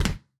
LandOnGround.wav